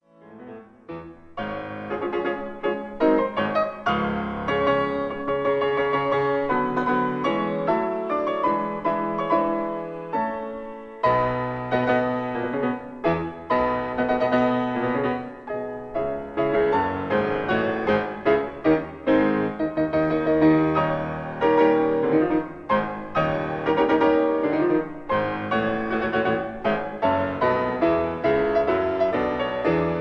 This is a 1960 recording